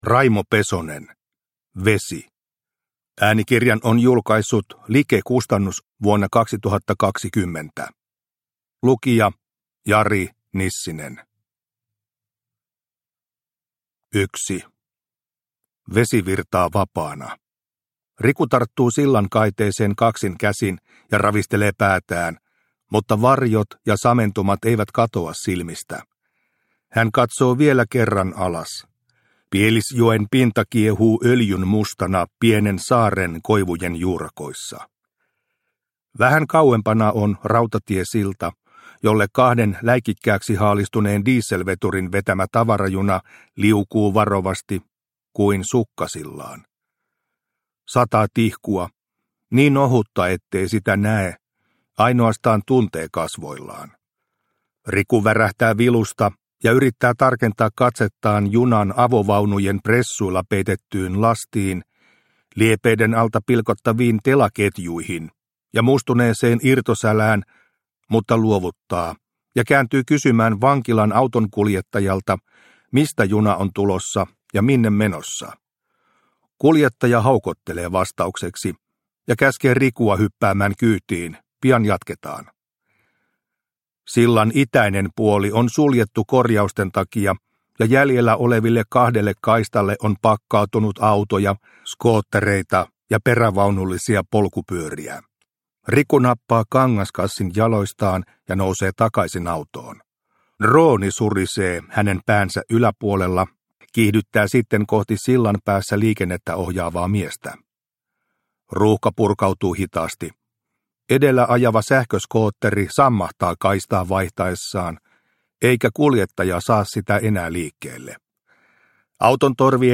Vesi – Ljudbok – Laddas ner